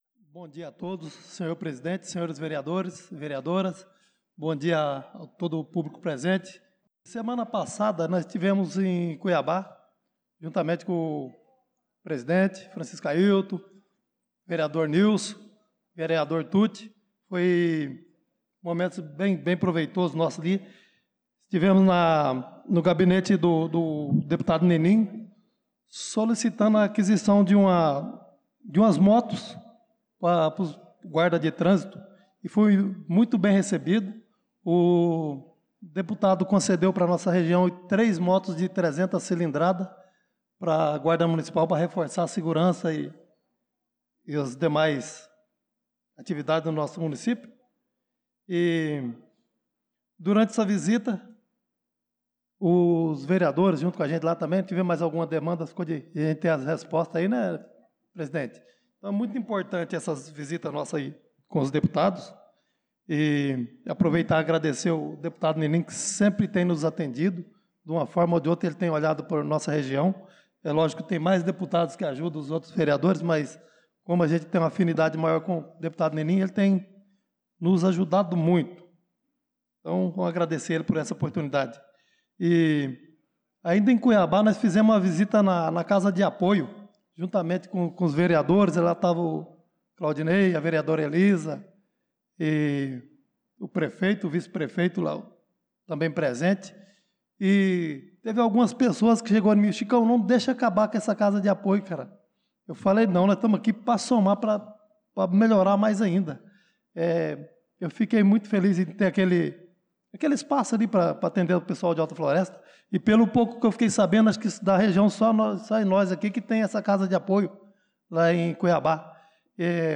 Pronunciamento do vereador Chicão Motocross na Sessão Ordinária do dia 25/03/2025